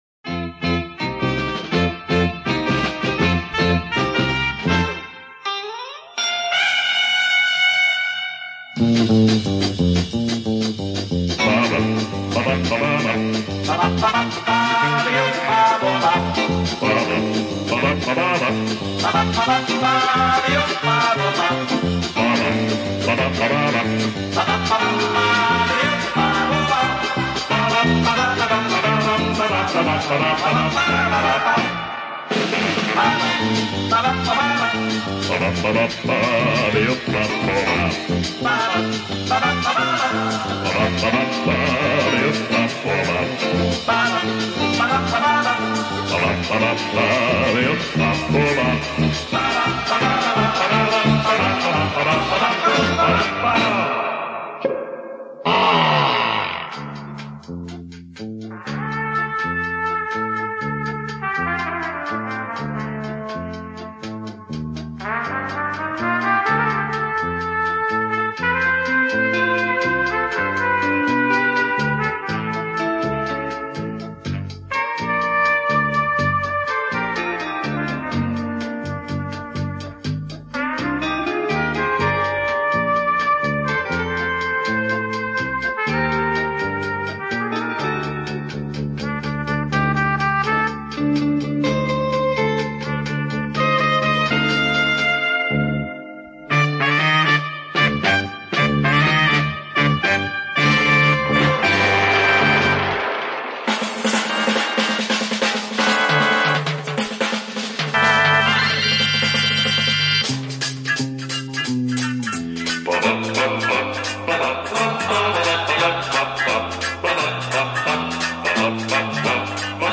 Даже без слов все предельно понятно.